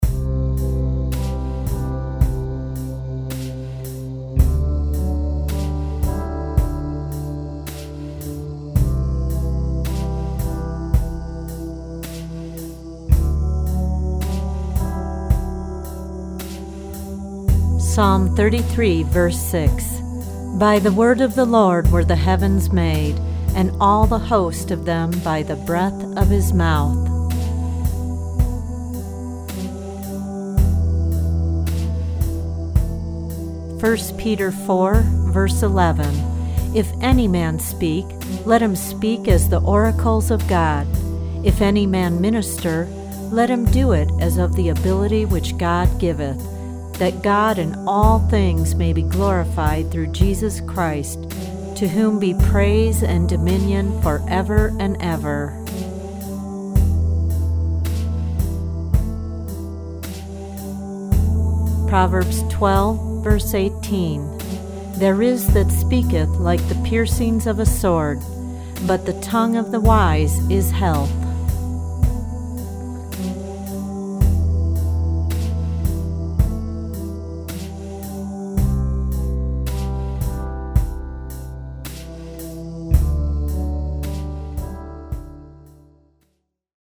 original anointed instrumental music on six CD’s.